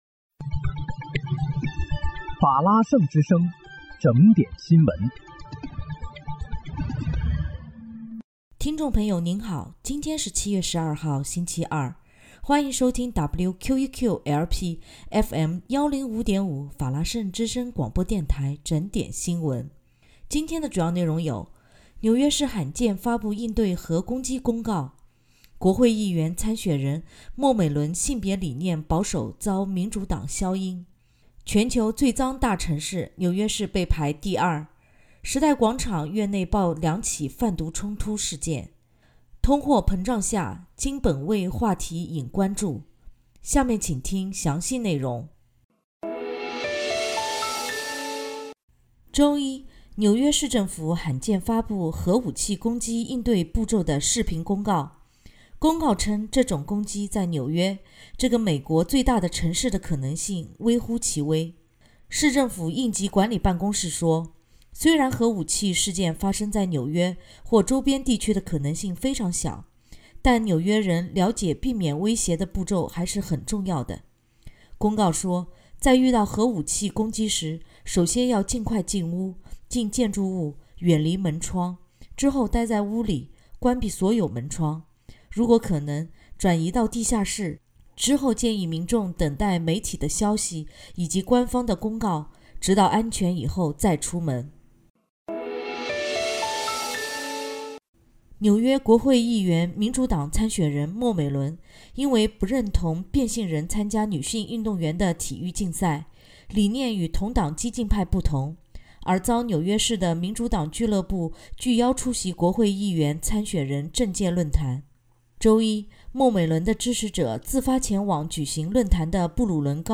7月12日（星期二）纽约整点新闻